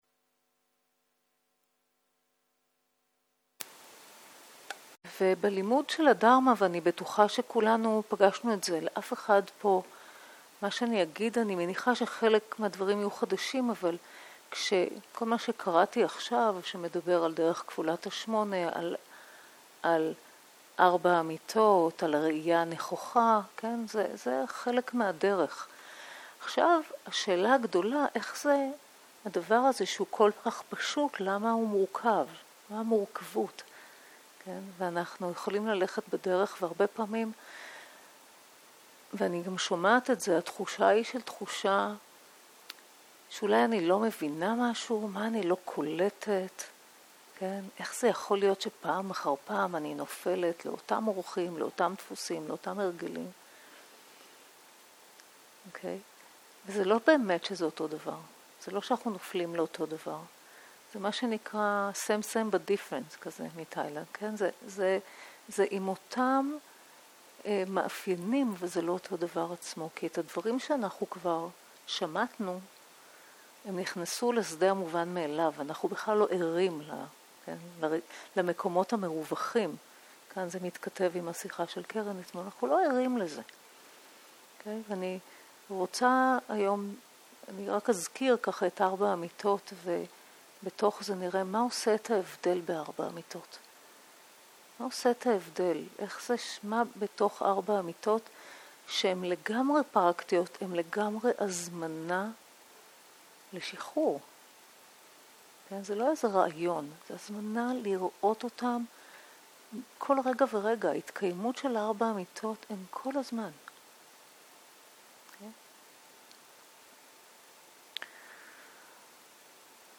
שיחת דהרמה
סוג ההקלטה: שיחות דהרמה